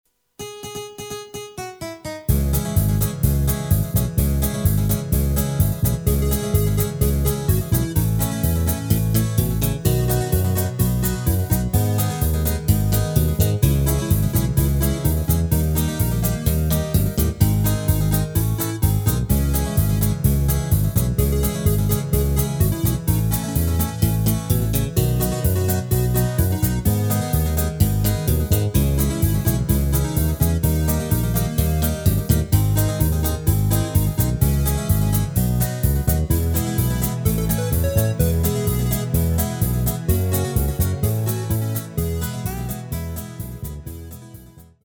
Rubrika: Pop, rock, beat
Karaoke